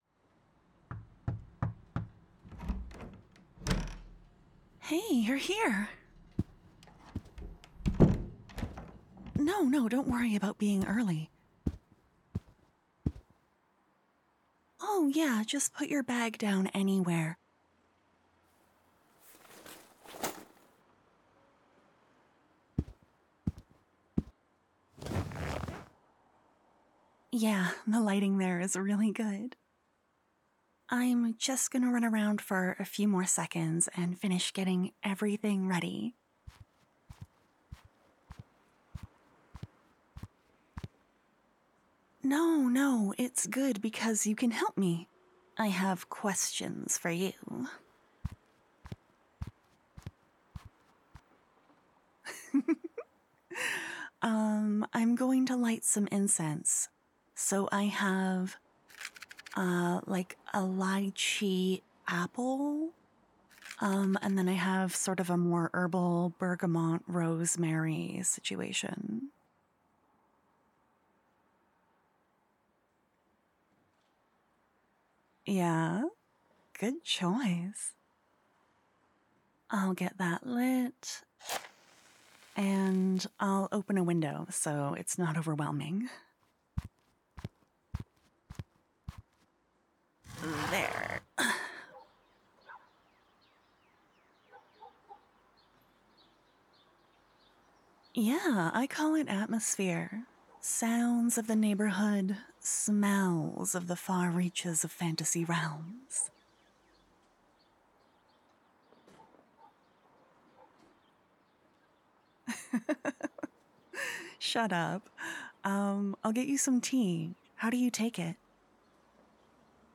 I tried to really make this 'feel' like a casual hang with the sounds and stuff.